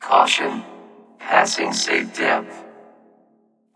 AI_Depth_Warning_1_OLD.ogg